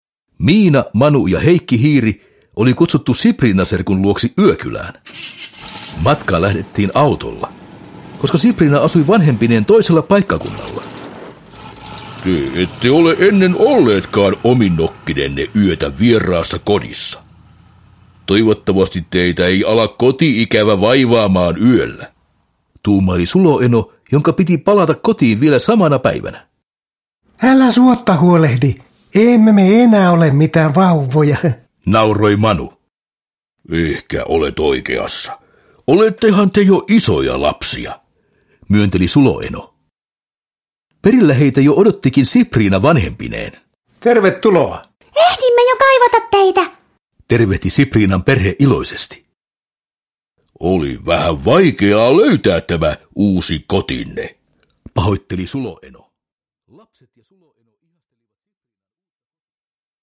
Miina ja Manu yökylässä – Ljudbok – Laddas ner